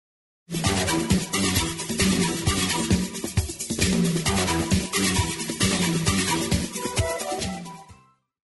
Ik weet wel dat het een pittig tempo had.
Panpipes melodie.